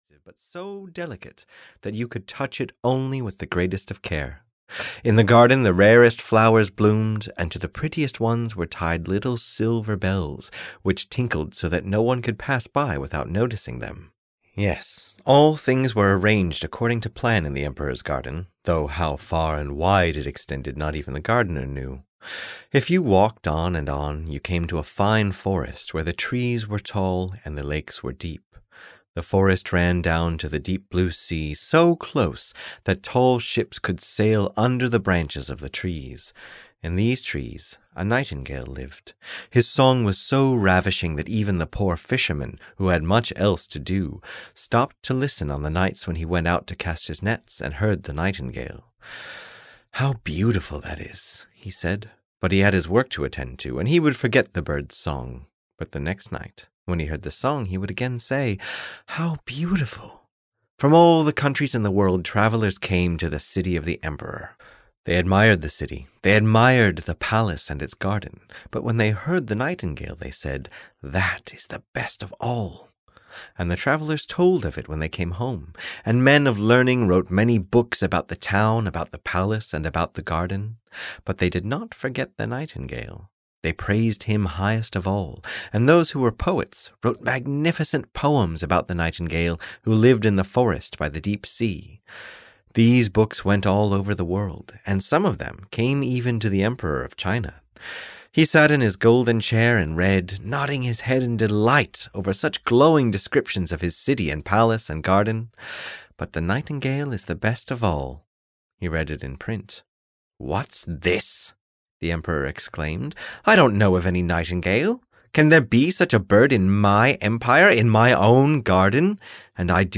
The Nightingale (EN) audiokniha
Ukázka z knihy